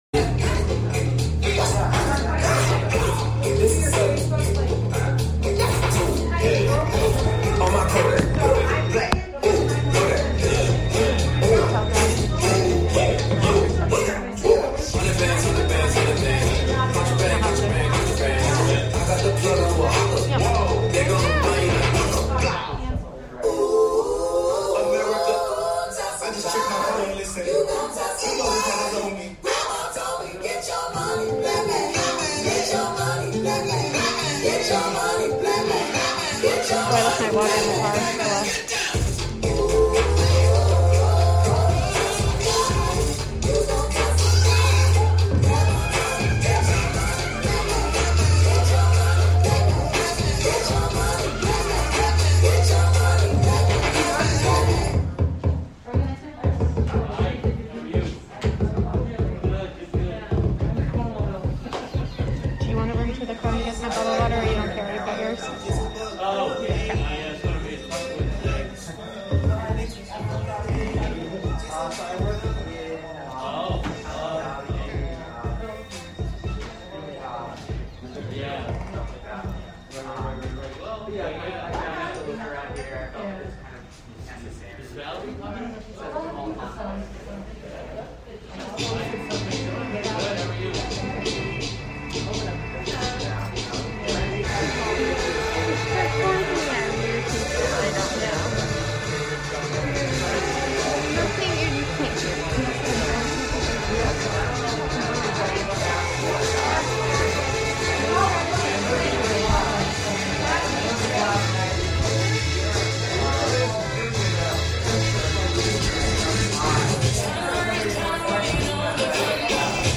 A live broadcast from the Black Live Matters rally and march in Cairo, NY.